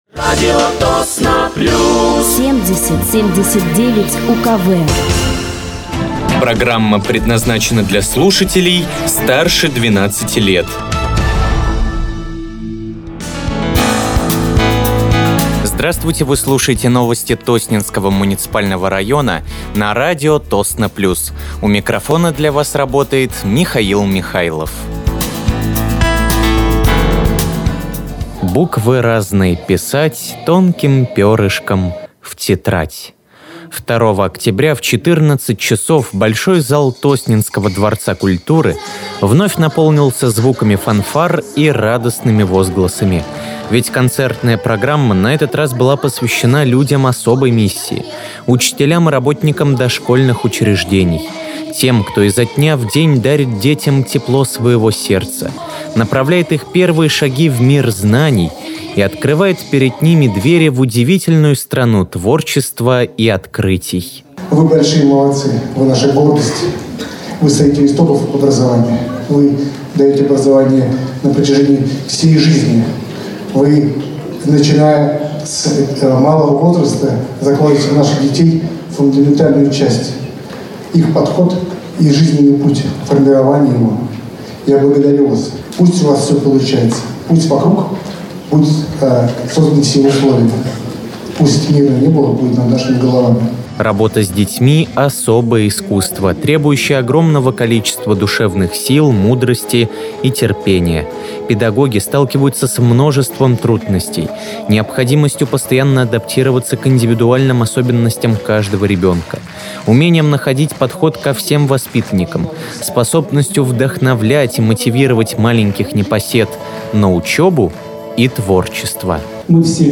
Выпуск новостей Тосненского муниципального района от 08.10.2025
Вы слушаете новости Тосненского муниципального района на радиоканале «Радио Тосно плюс».